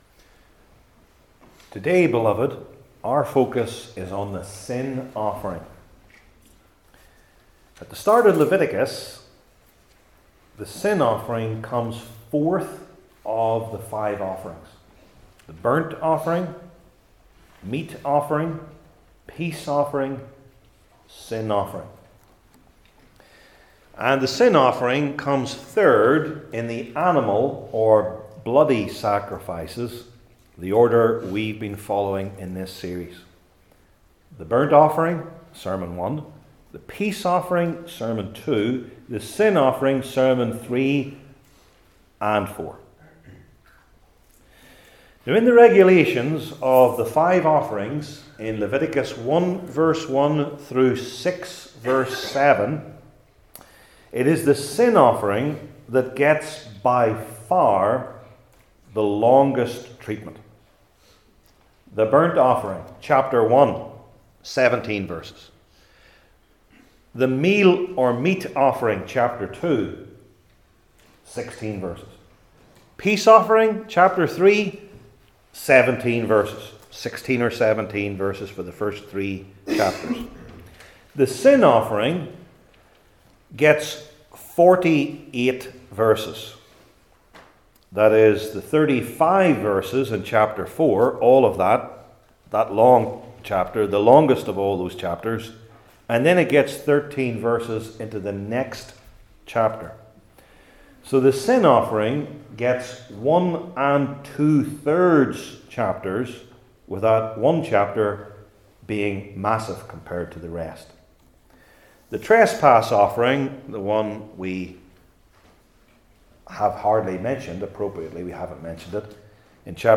Leviticus 4:22-5:13 Service Type: Old Testament Sermon Series I. The Need for the Sin Offering II.